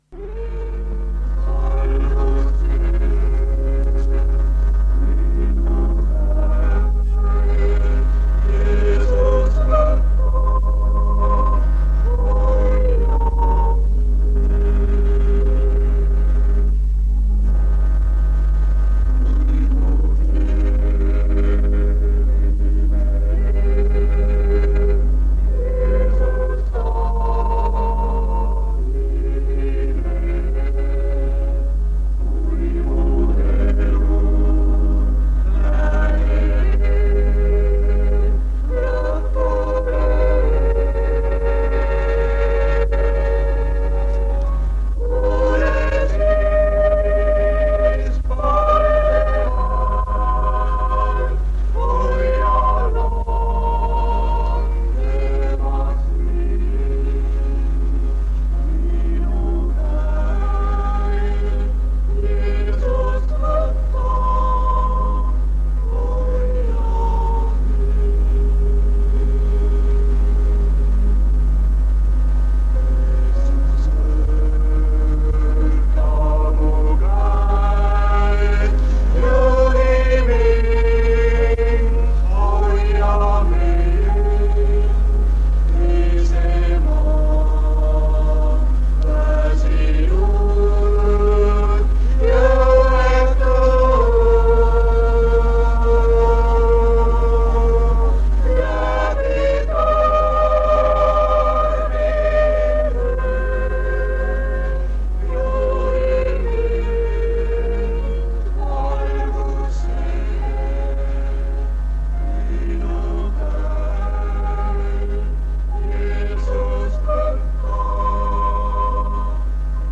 Lõpupoole veel nais-solistilt laul ja lõpusõnad.
Tunnistuste koosolek vanalt lintmaki lindilt. Taustal on sahinat...